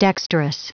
Prononciation du mot dexterous en anglais (fichier audio)
Prononciation du mot : dexterous